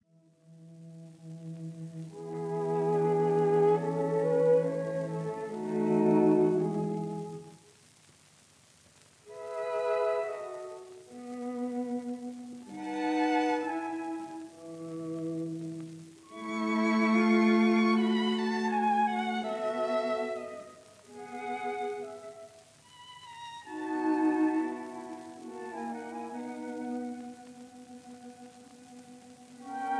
violins
viola
cello
in E major — Grave